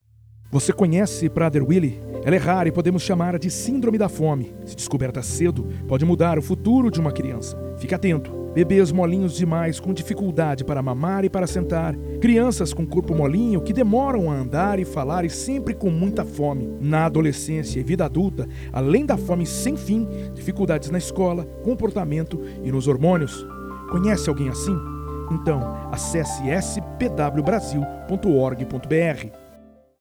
Para apoiar a divulgação, a Associação Brasileira de Prader-Willi preparou um spot de 30 segundos, disponível gratuitamente para download pelas rádios interessadas. O material tem como objetivo informar e mobilizar a sociedade sobre a importância do reconhecimento e tratamento da síndrome.
Spot_Sindrome_de_Prader_Willi_PWS.mp3